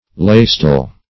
Meaning of laystall. laystall synonyms, pronunciation, spelling and more from Free Dictionary.
Search Result for " laystall" : The Collaborative International Dictionary of English v.0.48: Laystall \Lay"stall`\, n. 1.